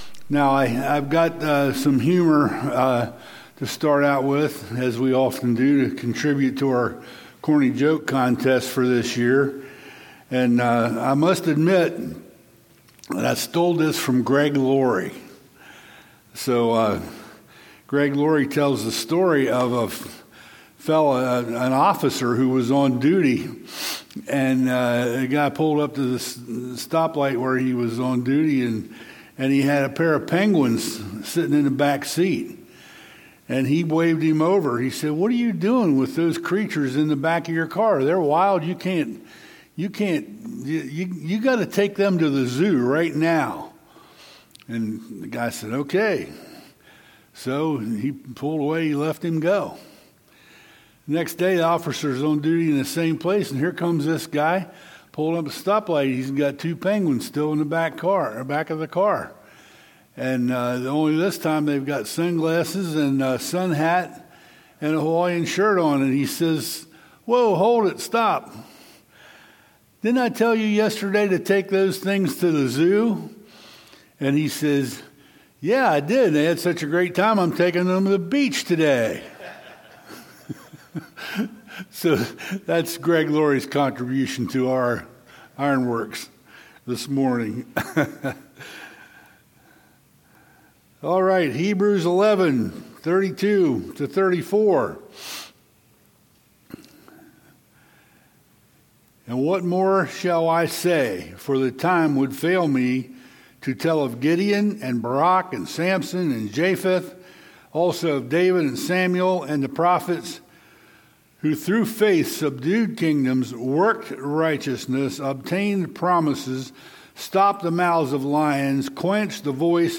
A message from the series "Ironworks."